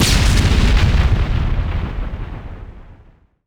grenadeexplode.wav